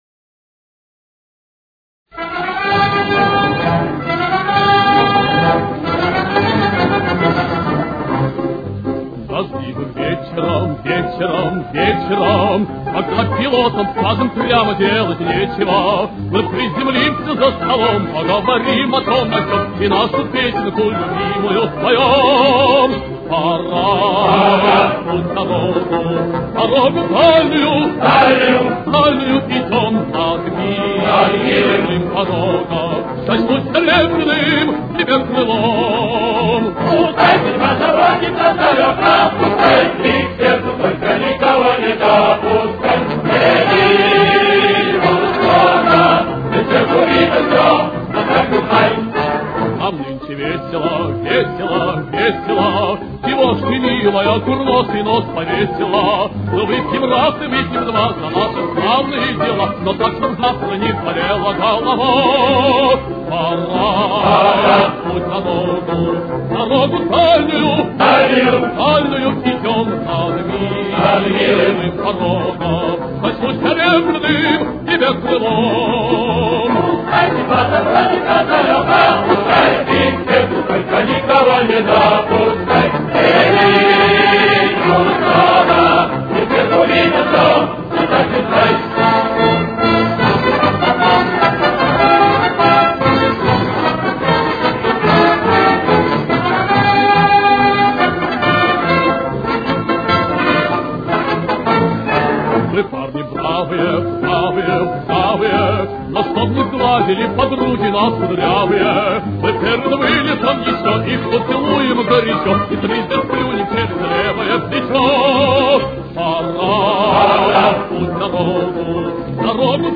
с очень низким качеством (16 – 32 кБит/с)
До мажор. Темп: 147.